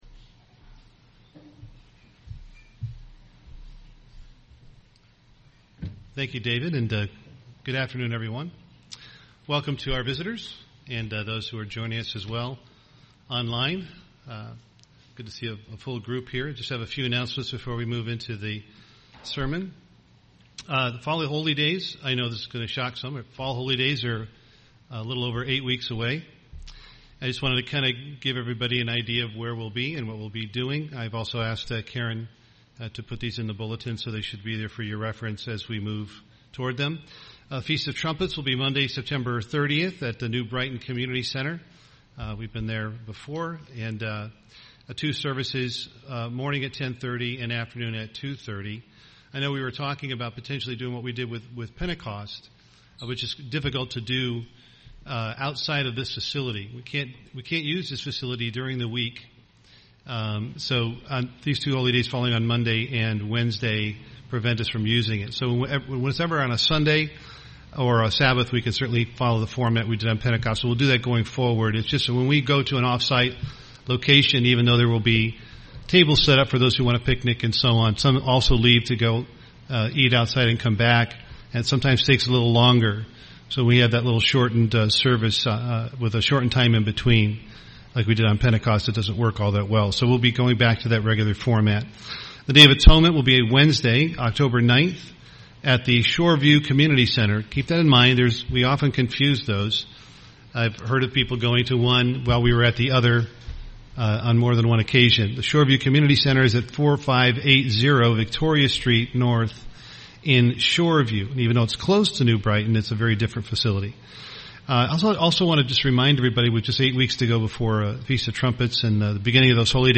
UCG Sermon gossip Gossiping Studying the bible?